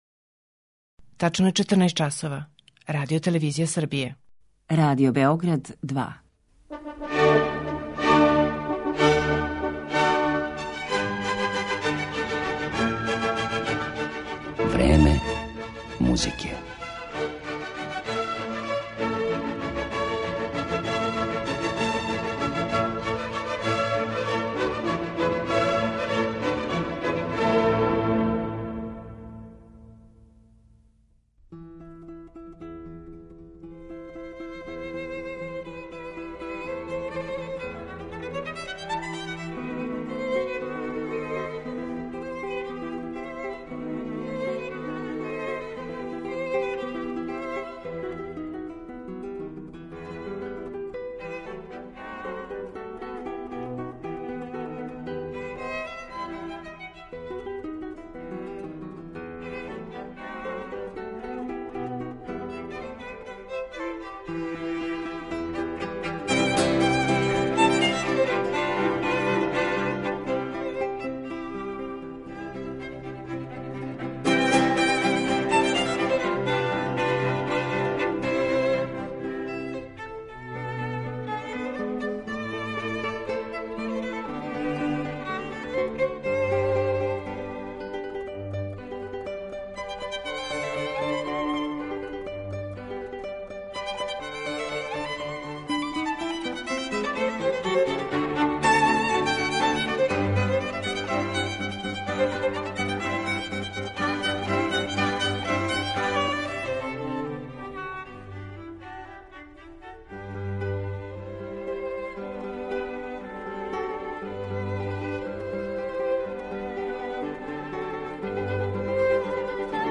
Данас ћемо га представити квинтетима за гитару и гудачки квартет Луиђија Бокеринија.